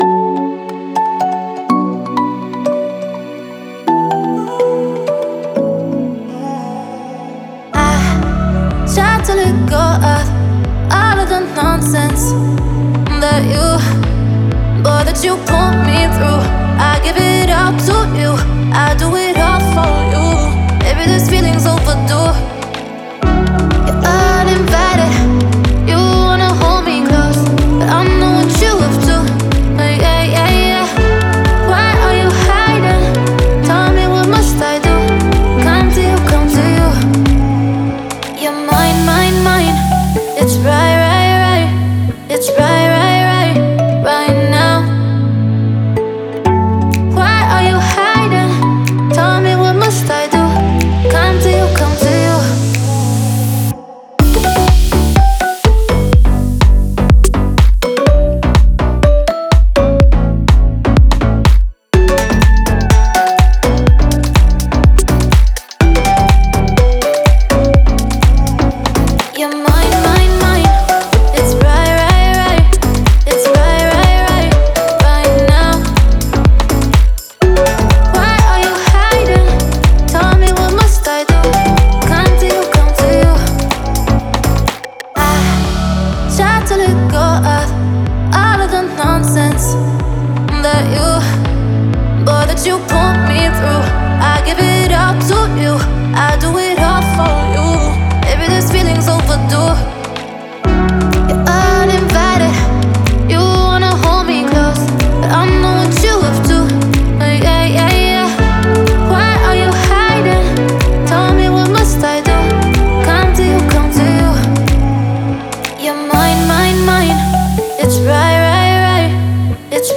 House, Energetic, Epic, Euphoric, Happy